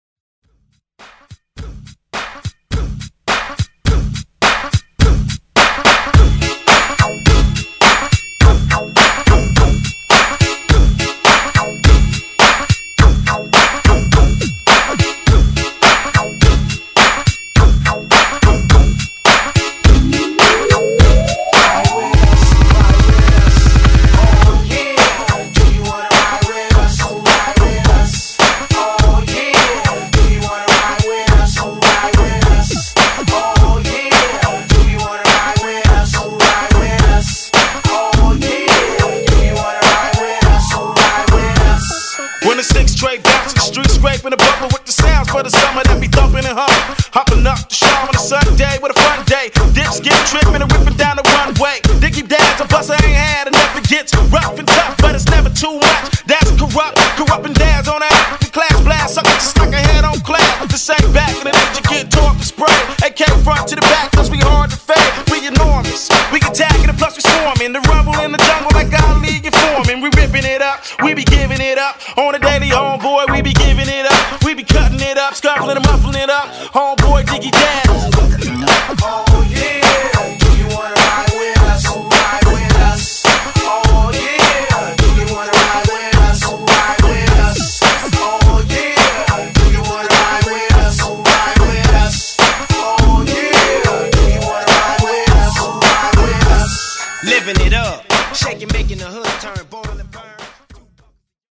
Genre: EDM
Clean BPM: 128 Time